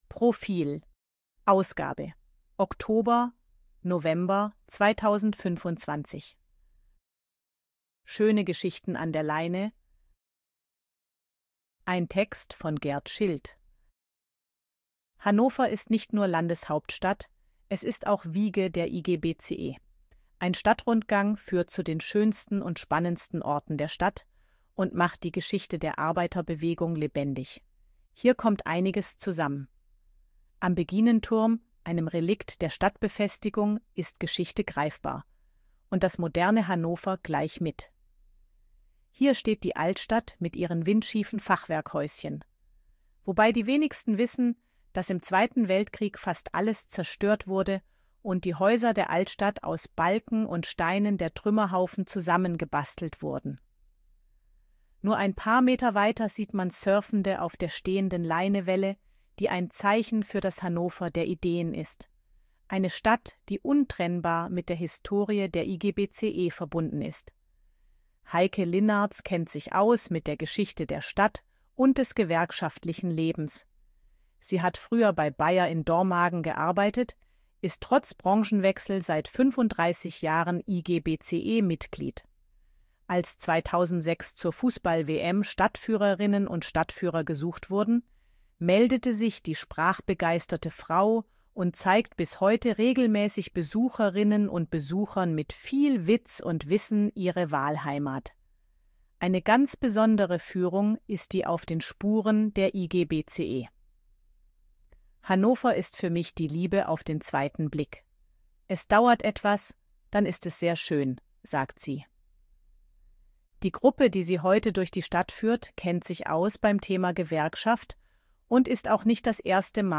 Artikel von KI vorlesen lassen
ElevenLabs_255_KI_Stimme_Frau_Betriebsausflug.ogg